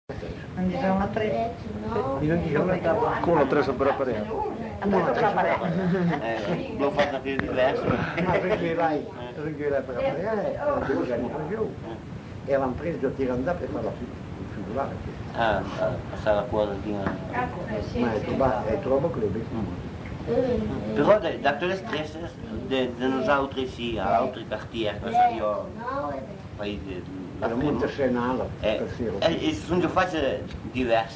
Aire culturelle : Val Varaita
Lieu : Bellino
Genre : témoignage thématique